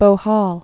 (bō-hôl)